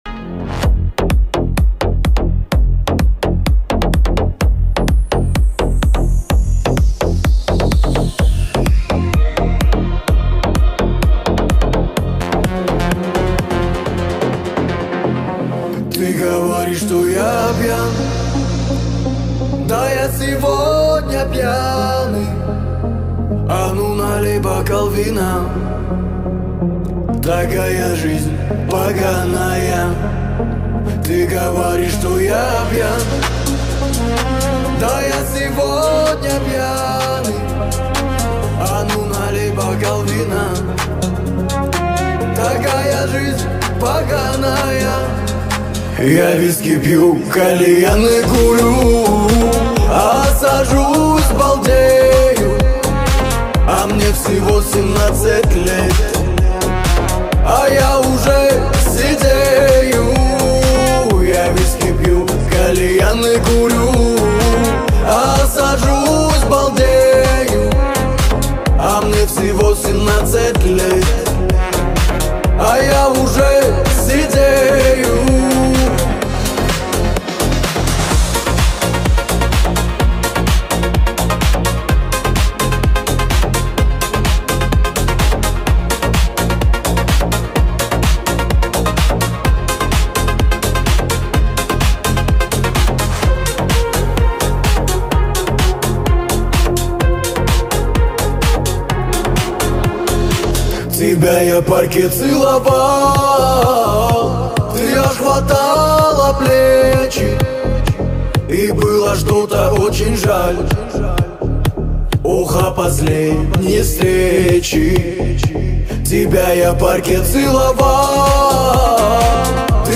Композиция звучит чисто и подходит для любого настроения.